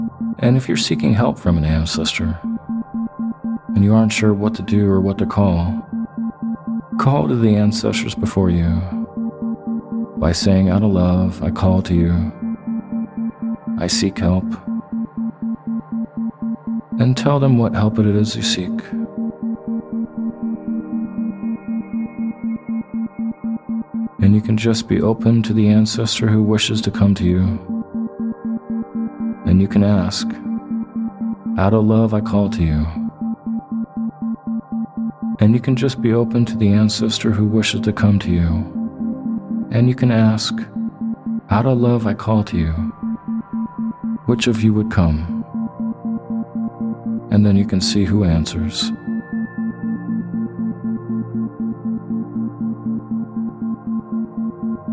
Connecting To Your Ancestors (After Life Connection) With Isochronic Tones